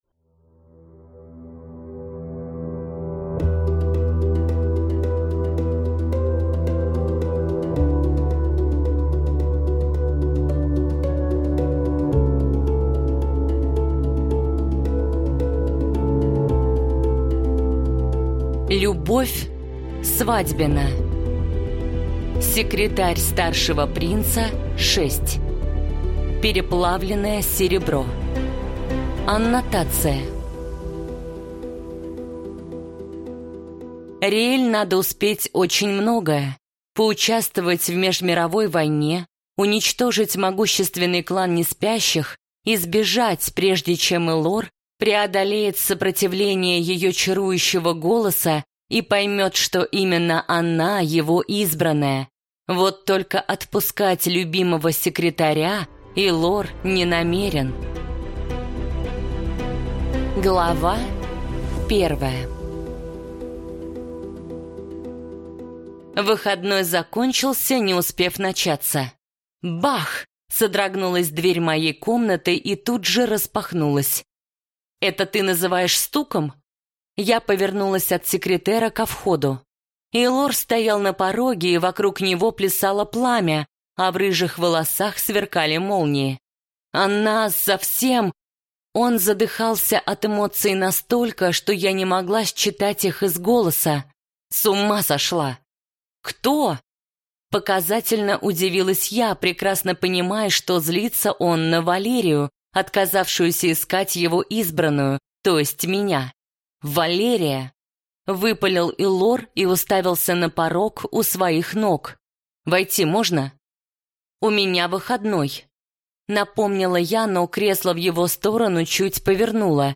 Аудиокнига Секретарь старшего принца 6. Переплавленное серебро | Библиотека аудиокниг